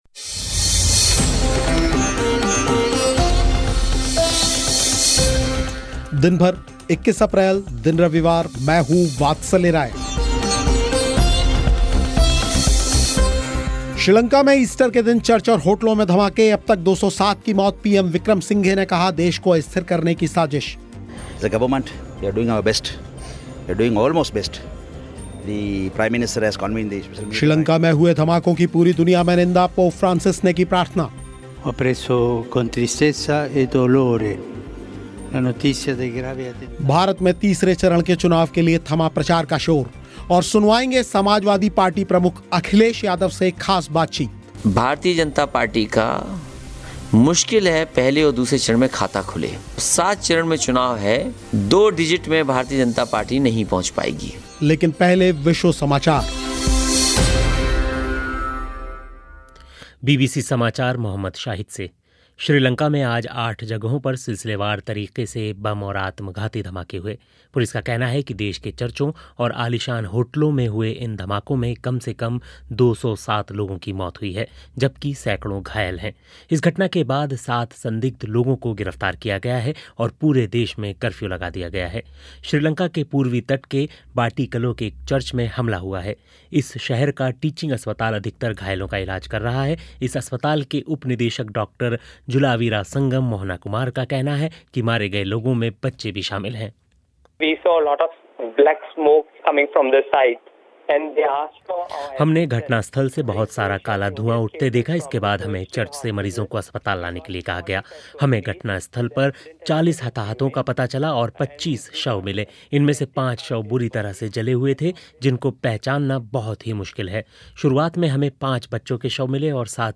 समाजवादी पार्टी प्रमुख अखिलेश यादव से ख़ास बातचीत